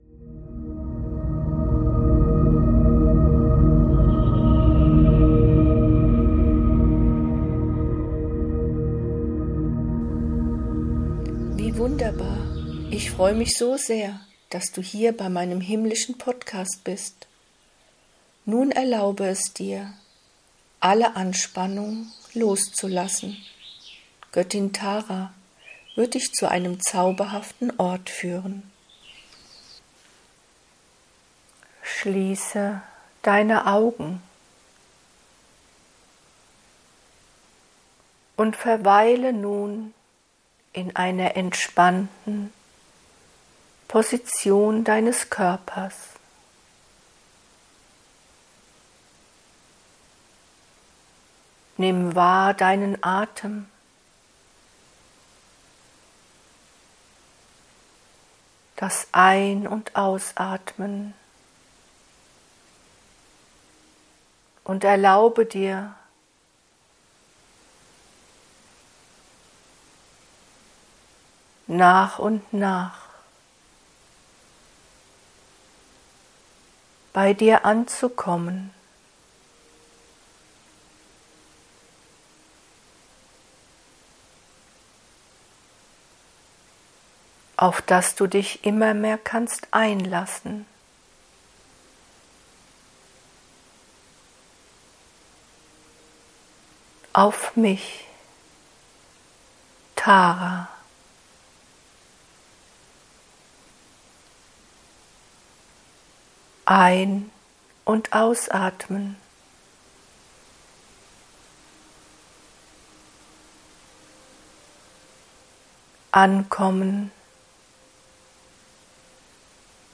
In meinem Podcast findest du durch mich direkt gechannelte Lichtbotschaften. Wundervoll geeignet zum meditieren, vom Alltag abschalten und abtauchen in eine andere Ebene des Seins.
- - - Wichtiger Hinweis: Bitte höre dir die Channelings in Ruhe an und vermeide es bitte, sie beim Autofahren anzuhören.